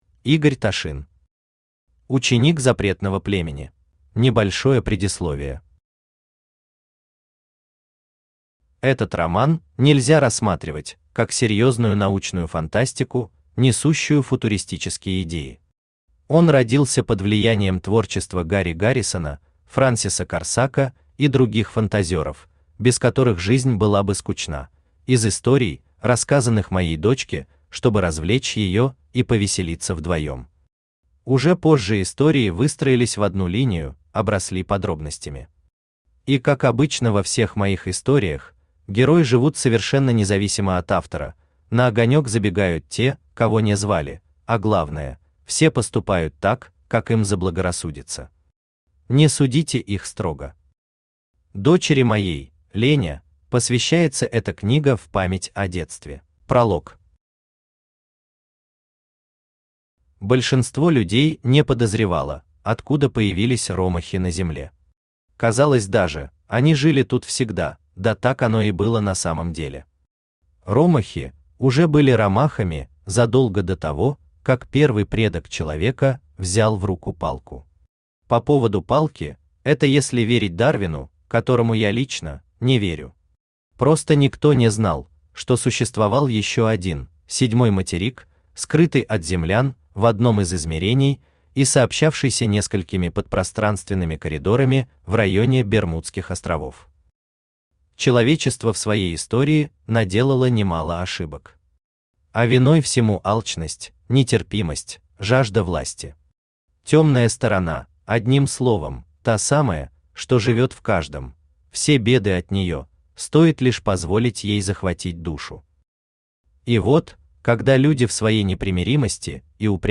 Aудиокнига Ученик запретного племени Автор Игорь Ташин Читает аудиокнигу Авточтец ЛитРес.